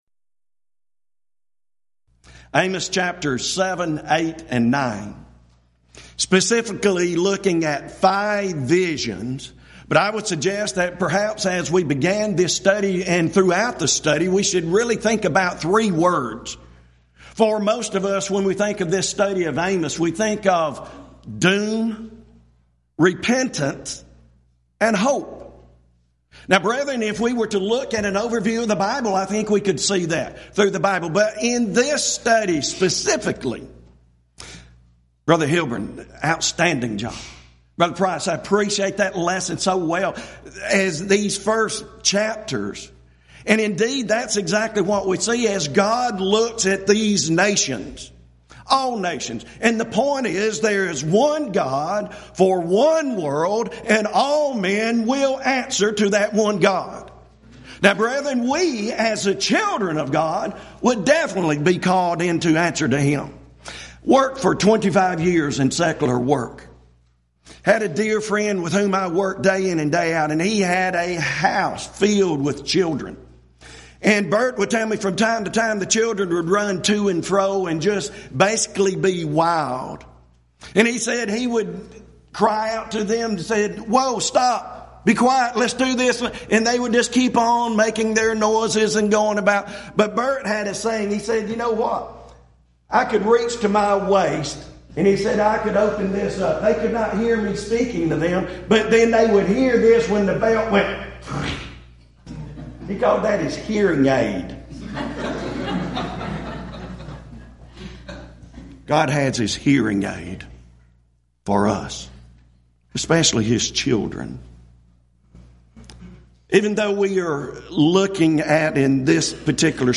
Event: 12th Annual Schertz Lectures Theme/Title: Studies in the Minor Prophets
If you would like to order audio or video copies of this lecture, please contact our office and reference asset: 2014Schertz10